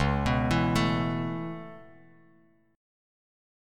C#m7#5 chord